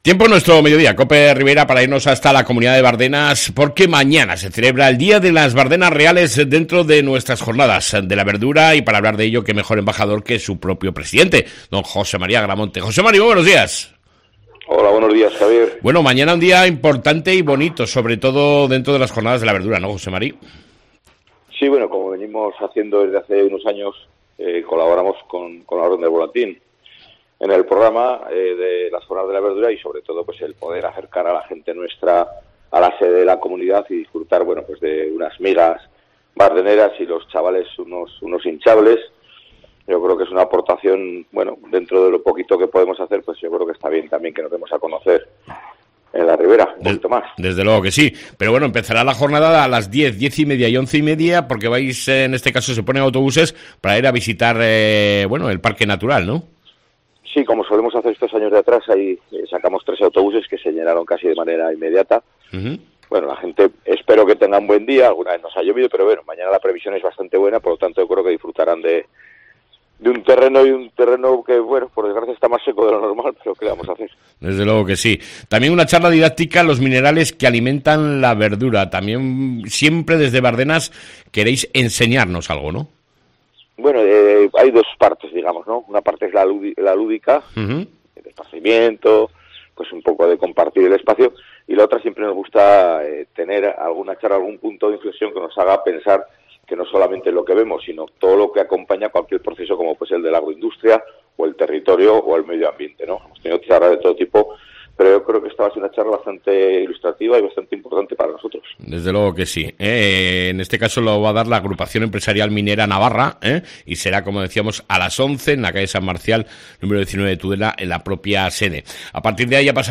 ENTREVISTA CON EL PRESIDENTE DE BARDENAS , JOSE Mª AGRAMONTE